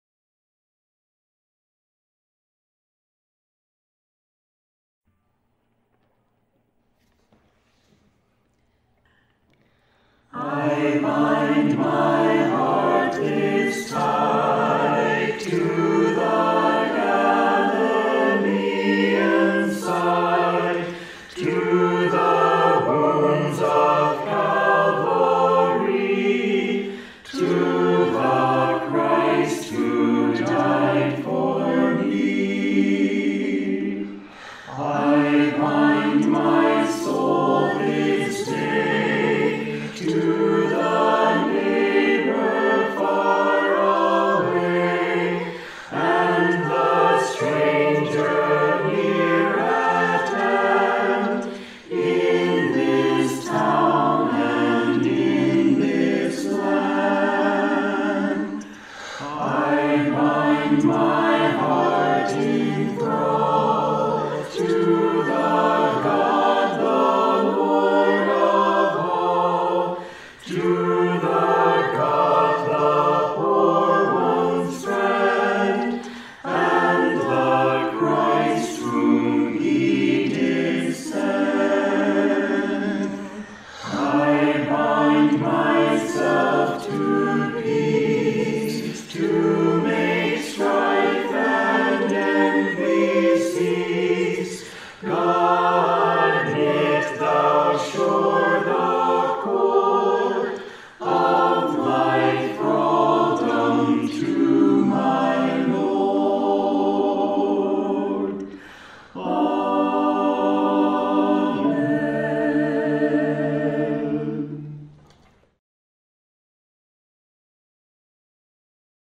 HYMN: Lauchlan McLean Watt
a-trissels-virtual-choir-i-bind-my-heart-this-tide.mp3